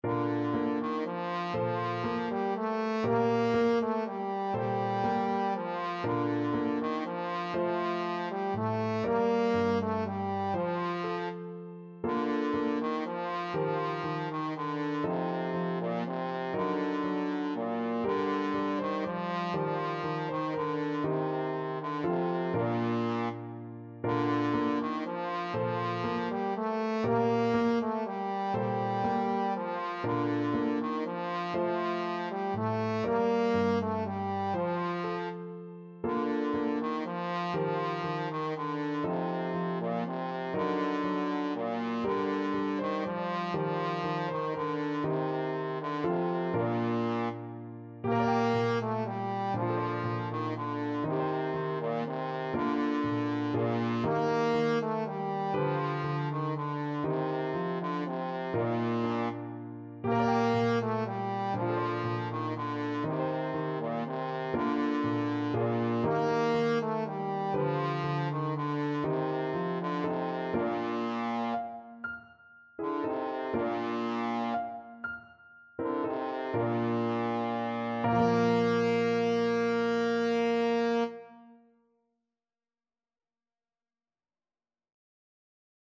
Trombone
El Noi de la Mare (The Child of the Mother) is a traditional Catalan Christmas song.
6/8 (View more 6/8 Music)
Bb3-Bb4
Bb major (Sounding Pitch) (View more Bb major Music for Trombone )
Lentissimo .= 40